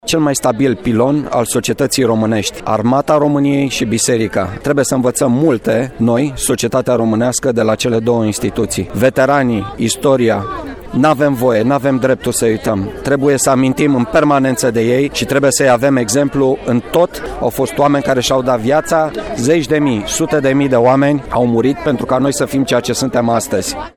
Un ceremonial militar și religios a avut loc la Cimitirul Șprenghi, unde, după alocuțiunile oficiale, au fost depuse coroane de flori.
Prefectul Județului Brașov, Marian Rasaliu.